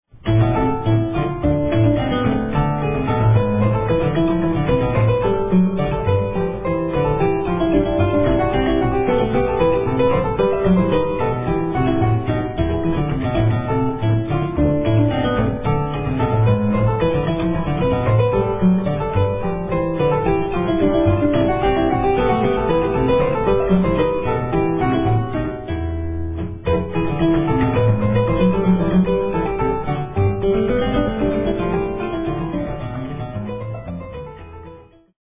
About the exclusive use of the Peau de Buffle stop
It's also very relaxing listening."